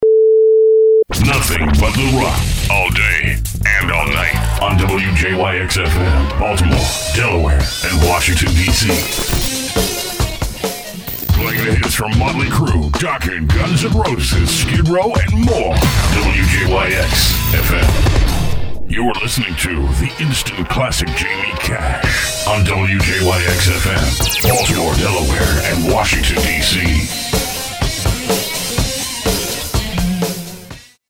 Category: Radio   Right: Personal
Tags: Voiceover Radio Voice voice artist Imaging internet imaging